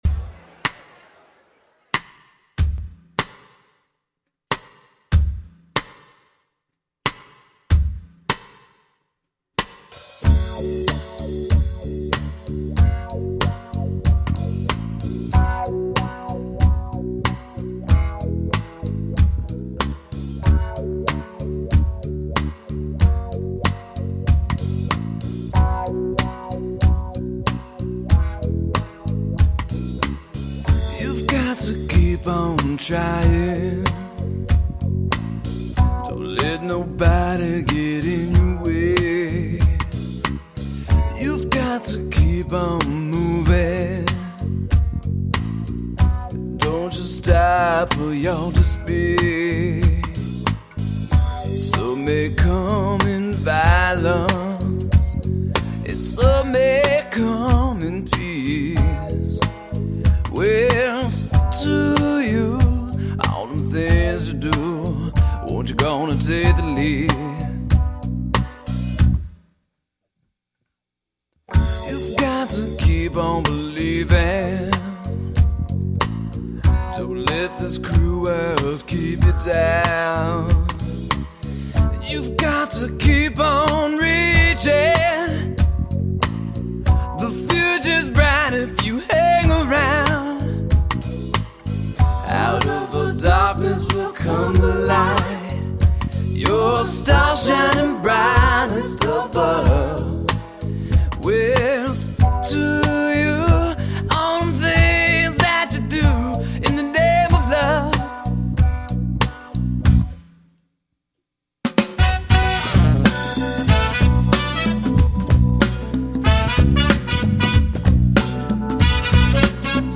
Vox/Guitar/Synth/Drum Programming
Alto and Soprano Saxes
Tenor Sax
Synth/Hammond